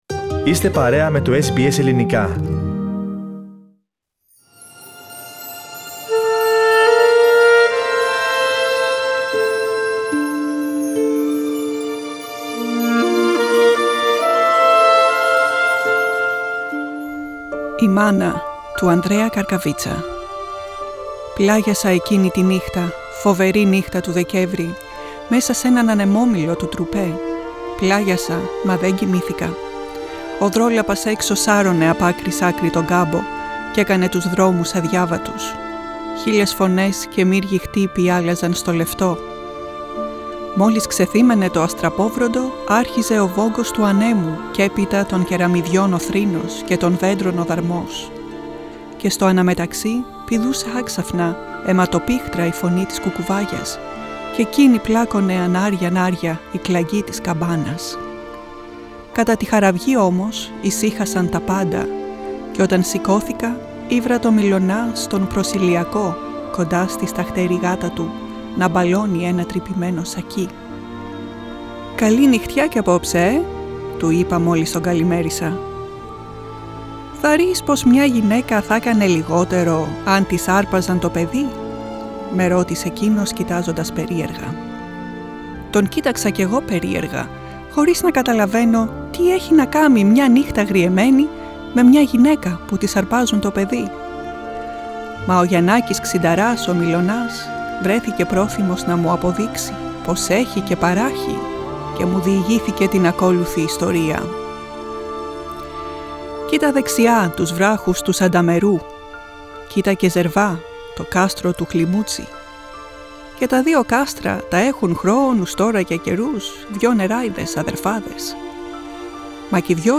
Ελληνικά διηγήματα